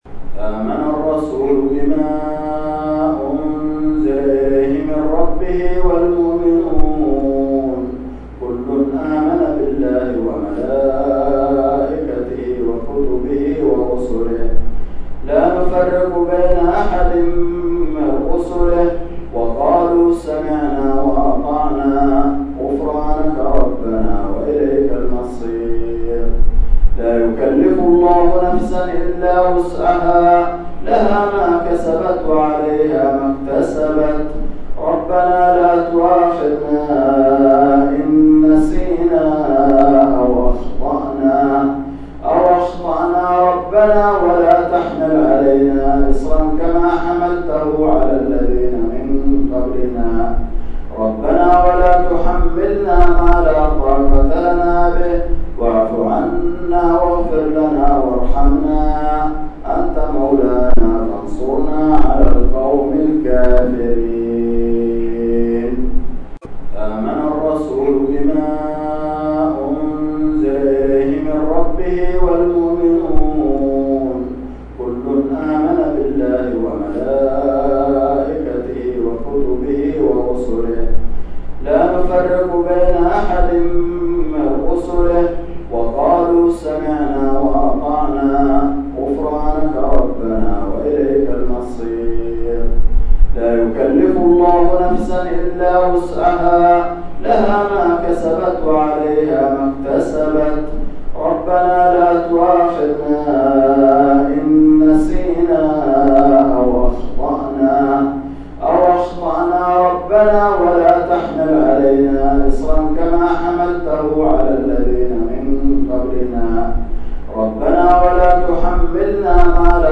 تلاوات